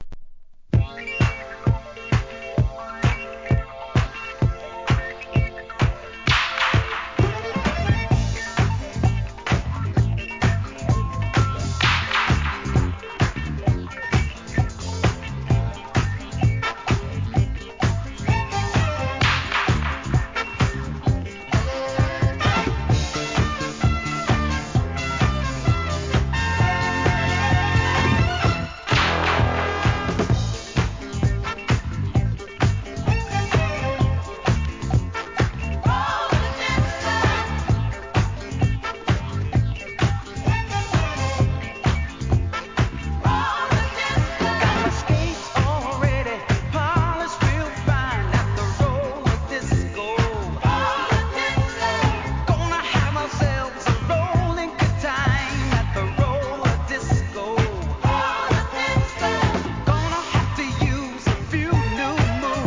¥ 440 税込 関連カテゴリ SOUL/FUNK/etc...